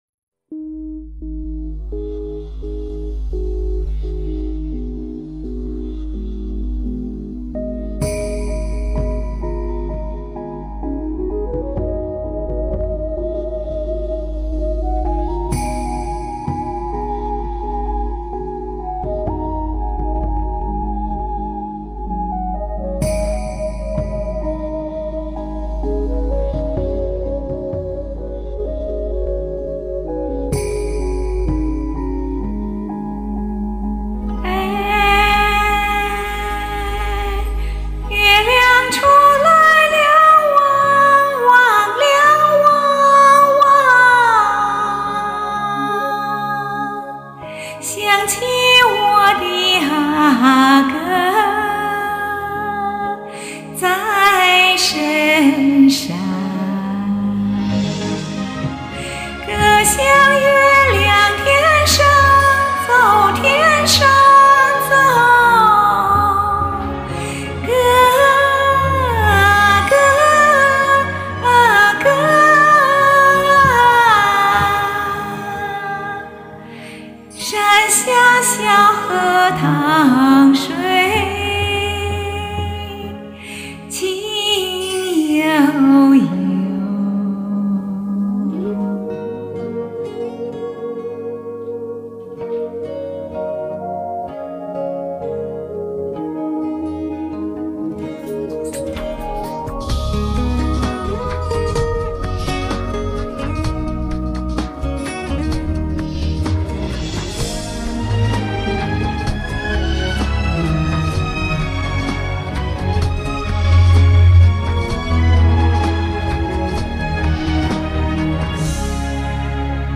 民歌不是俺的强项， 大家包容俺这捏着铜嗓的再创作吧！
第一首是为这次活动学唱和新录的，一直梦寐以求的云南民歌“小河淌水” 。